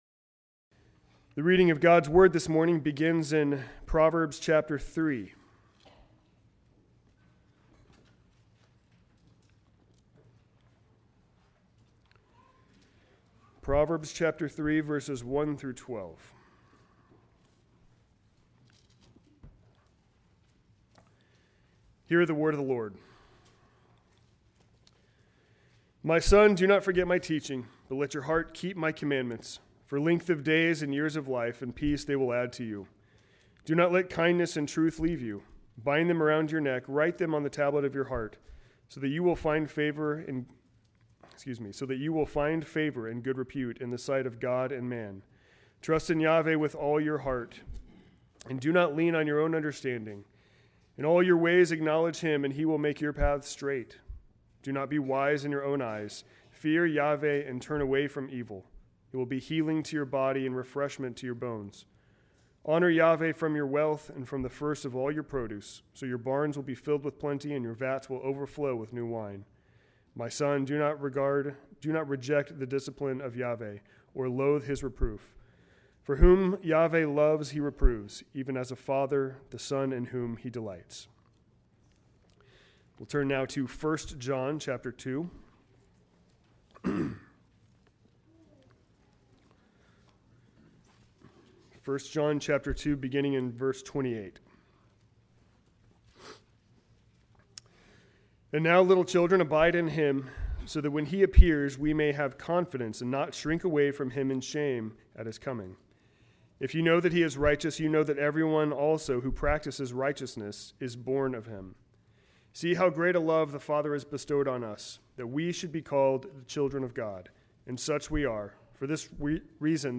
Sermons on the Family